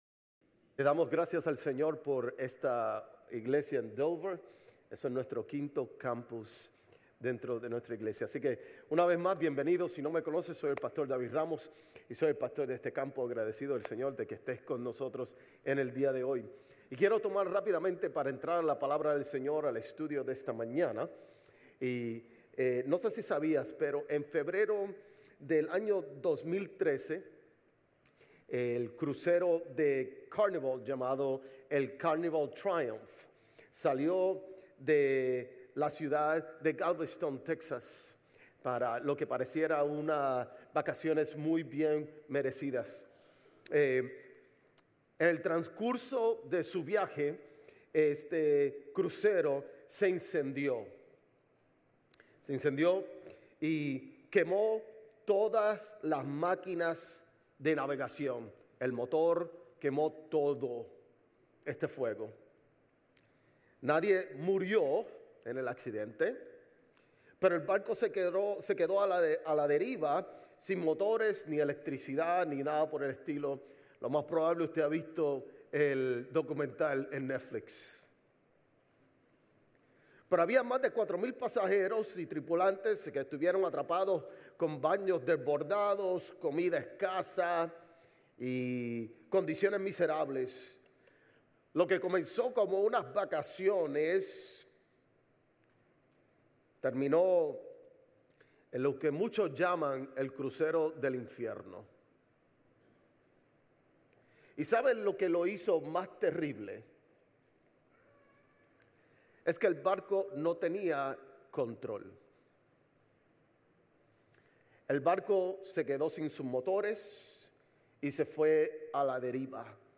Sermones Grace Español 9_21 Grace Espanol Campus Sep 22 2025 | 00:41:15 1x 00:00 / 00:41:15 Subscribe Share RSS Feed Share Link Embed